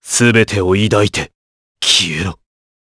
Clause_ice-Vox_Skill6_jp.wav